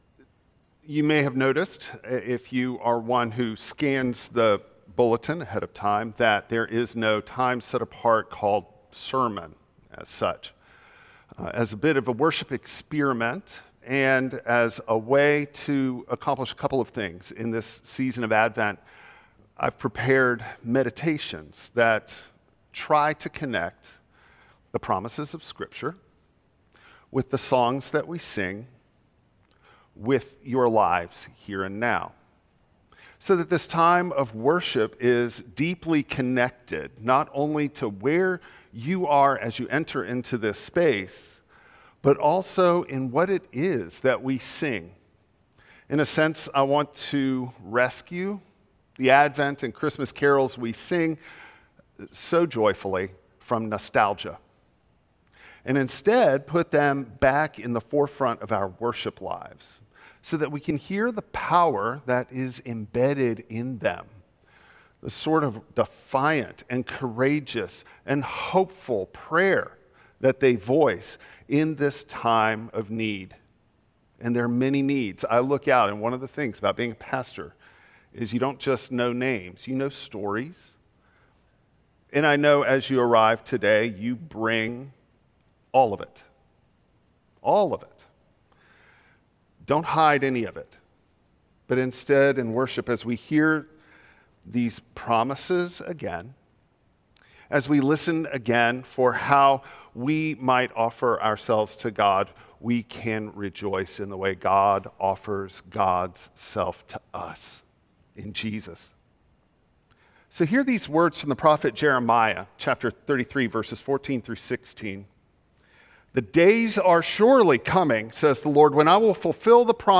Micah 5:2-5a Service Type: Traditional Service Advent hope sees beyond brokenness.